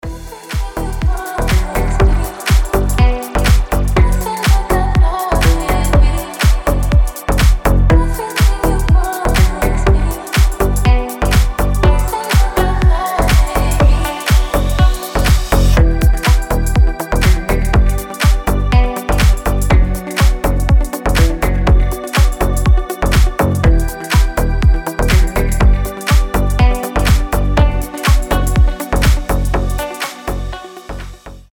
• Качество: 320, Stereo
красивые
deep house
атмосферные
расслабляющие